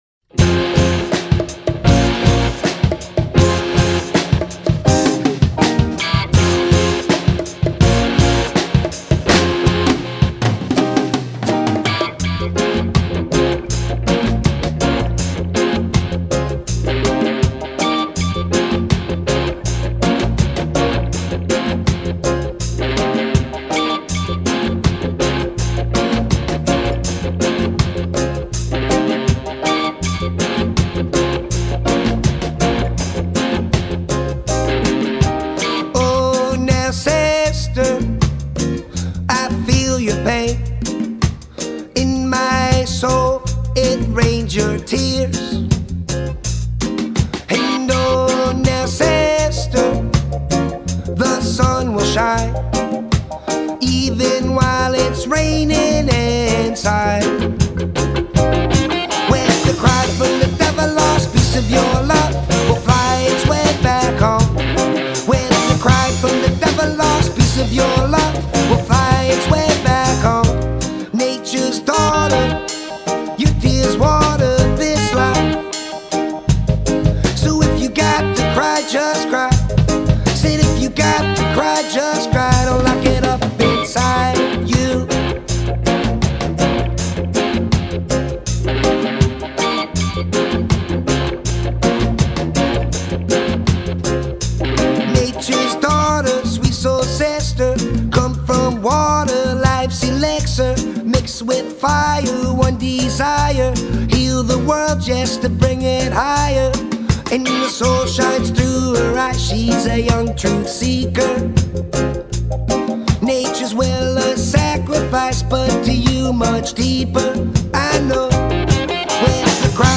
dance/electronic
rock style reggae fusion song
Folk
World music